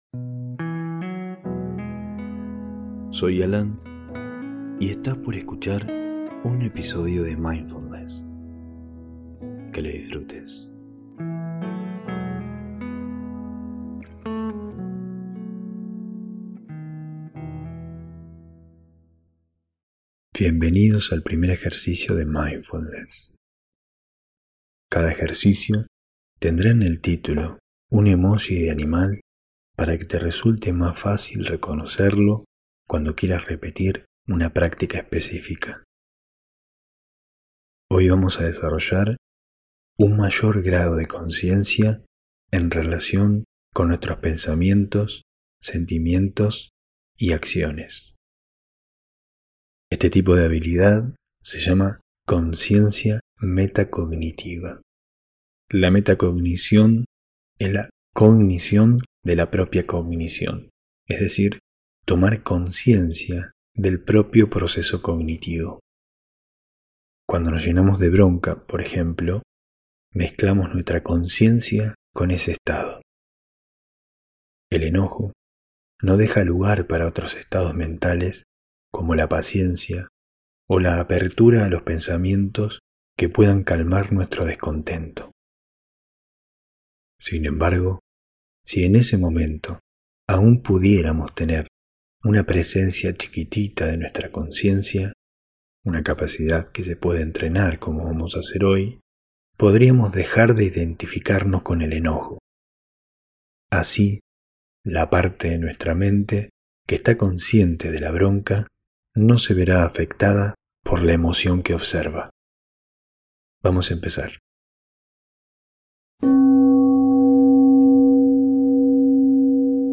Con esta meditación, aprendemos a tratar con nuestros pensamientos, con la suficiente conciencia como para mirarlos mientras pasan.